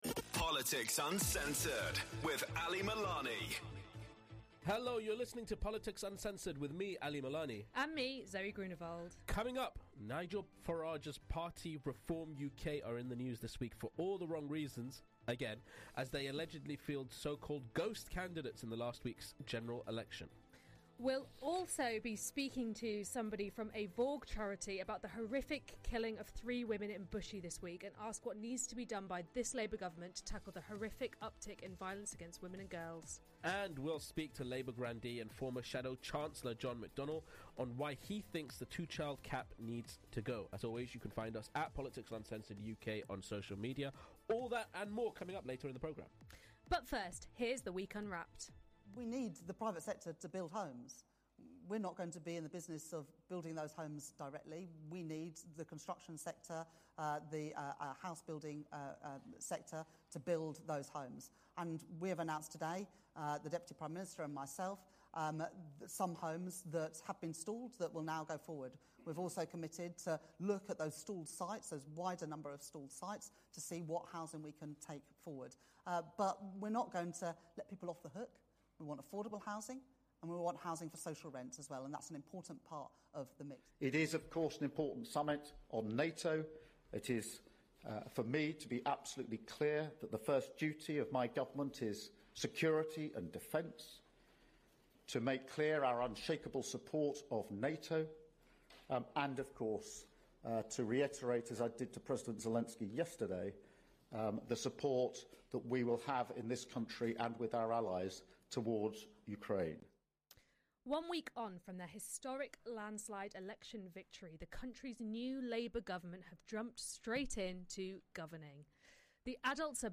The pair chat to former Labour Shadow Chancellor, John Mcdonnell, about why he thinks the current two-child benefit cap needs to be scrapped. They also speak to the VAWG campaign group THIS ENDS NOW following the horrific killings of three women in Bushey.